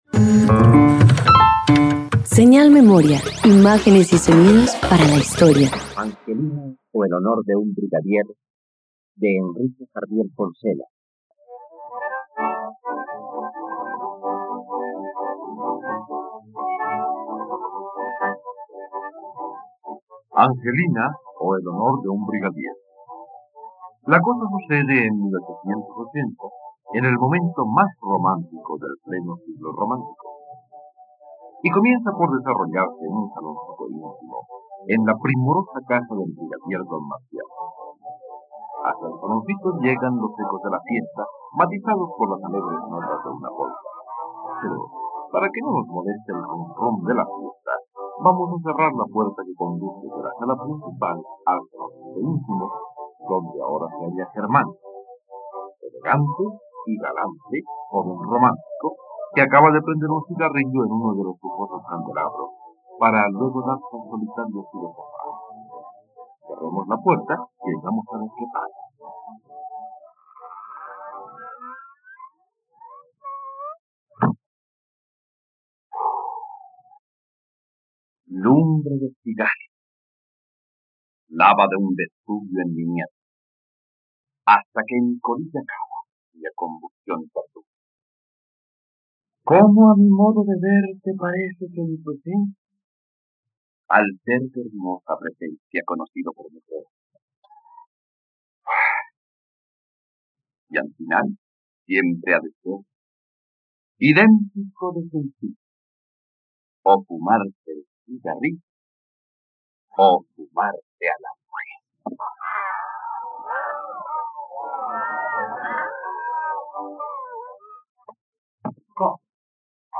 ..Radioteatro. Escucha la adaptación radiofónica de “El honor de un brigadier” de Enrique Jardiel Poncela por la plataforma RTVCPlay.